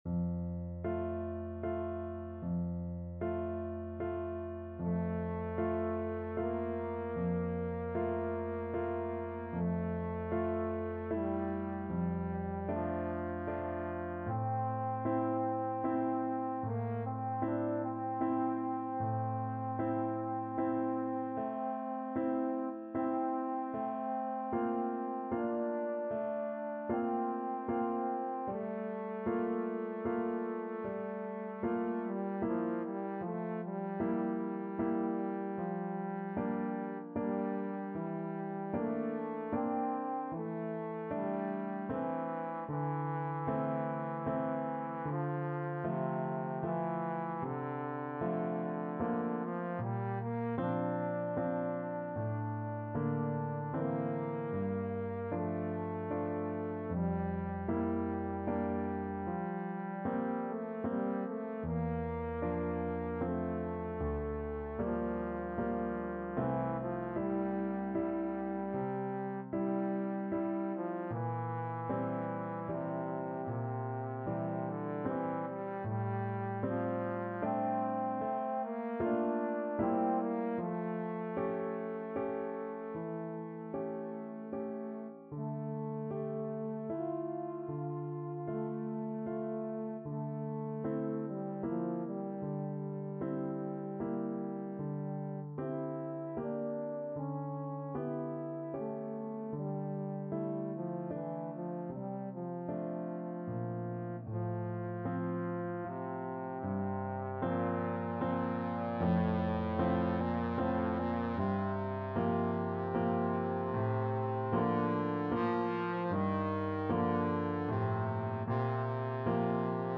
Trombone version
3/4 (View more 3/4 Music)
Adagio assai =76
A3-G5
Classical (View more Classical Trombone Music)